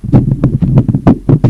pumpkin_low.ogg